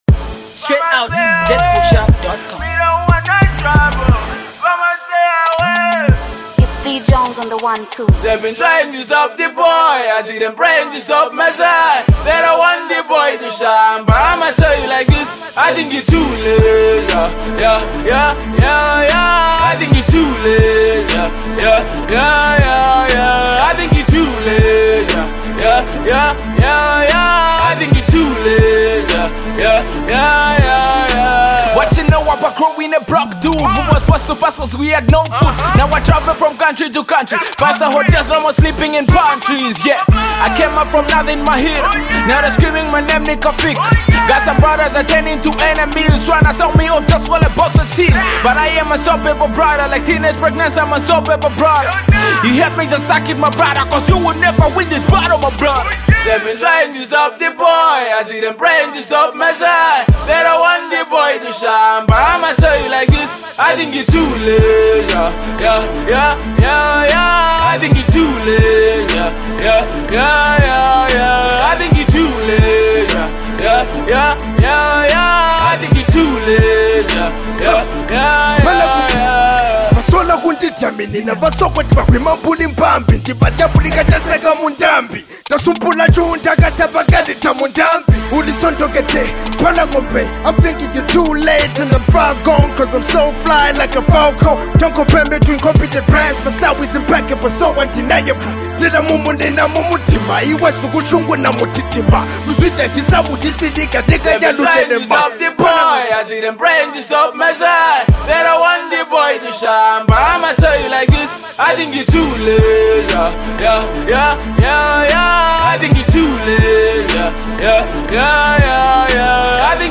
Top ravishing hip-hop banger
Get it below and enjoy real hip-hop.